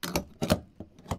putbacknozzle.ogg